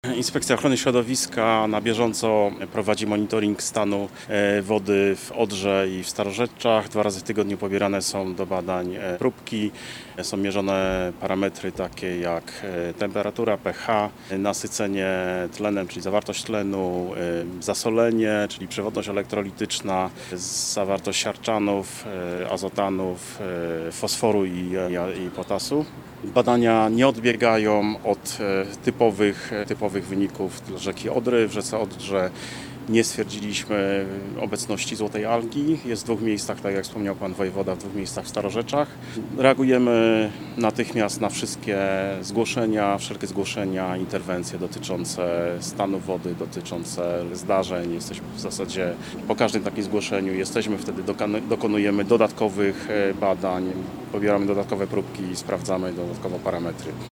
Wojewódzki Inspektor Ochrony Środowiska Mariusz Wojewódka mówi o bieżących działaniach.